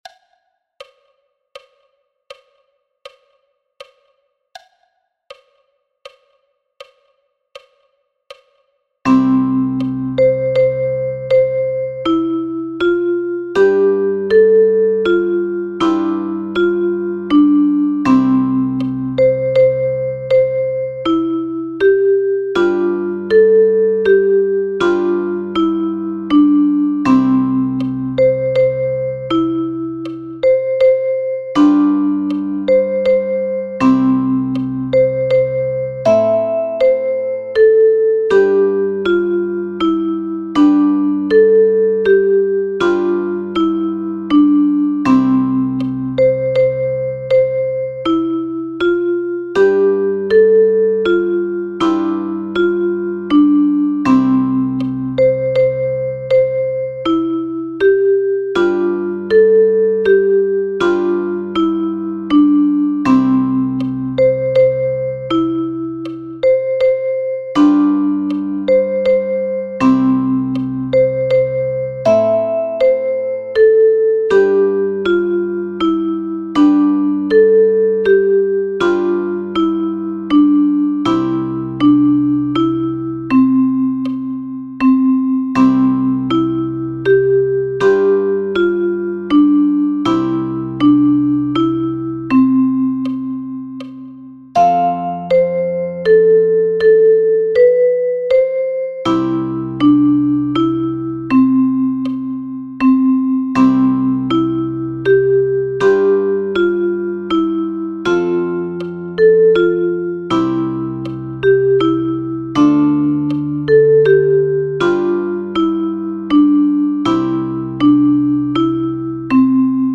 Online: MP3- Sounds der Lieder